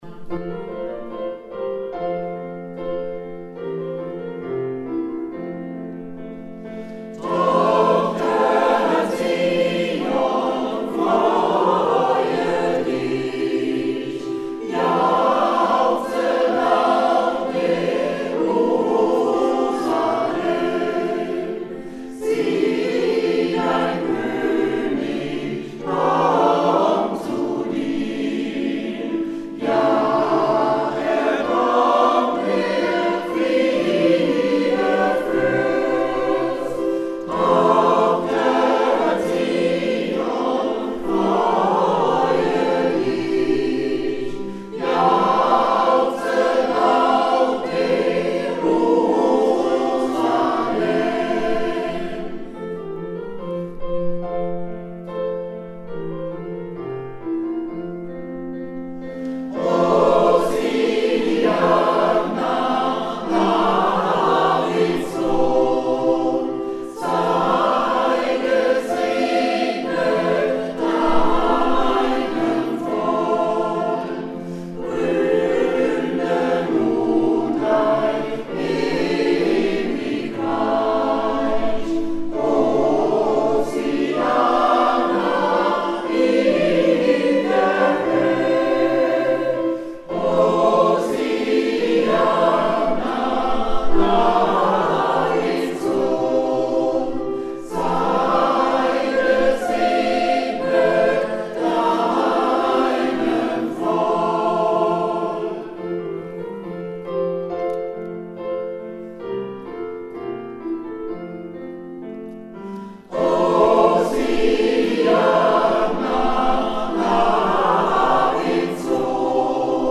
Zur Zeit besteht der Chor aus 42 Aktiven.
Liederaufnahmen des Kirchenchor: